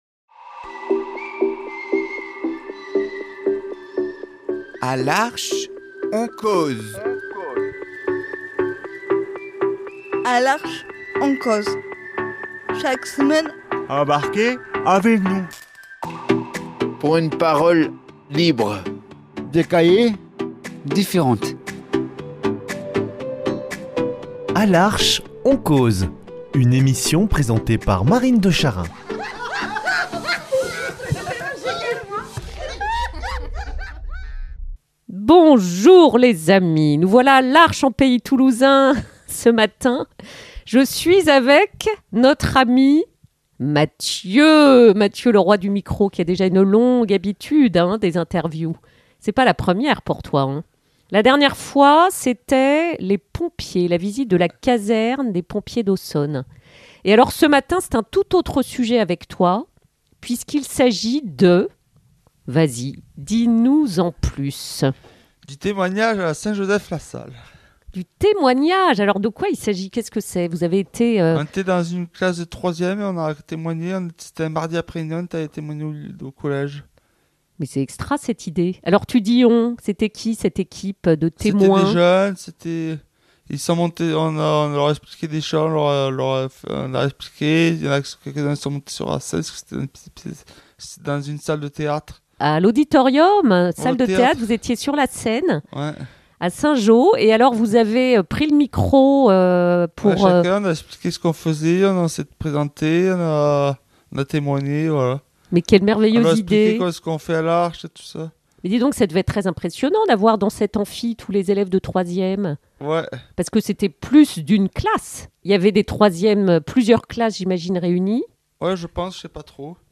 sont montés sur la scène de l’Auditorium du Collège St Joseph pour y témoigner, échanger, répondre aux questions, vibrer avec ce public de jeunes !